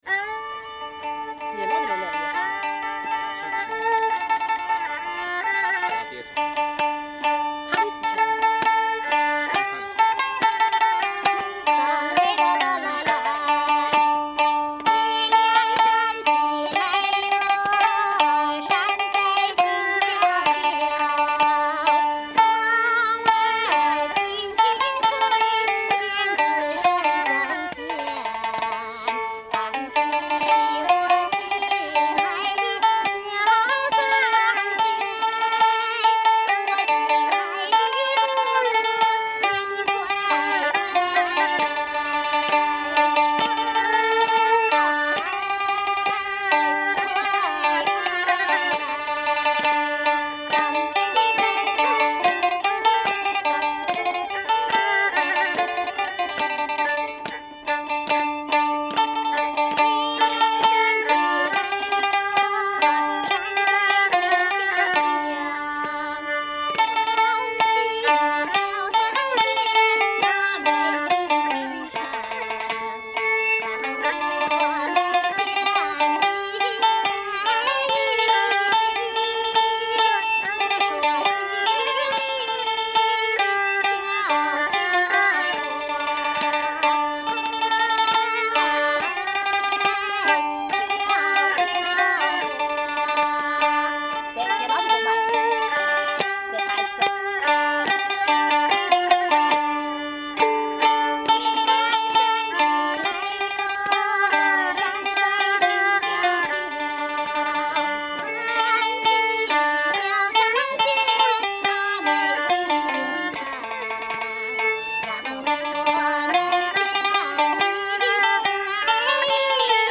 These are sounds from China, recorded November-December 2006
And from the southern city of Kunming, where some local musicians sat in the park in the evening and played
Another Chinese tune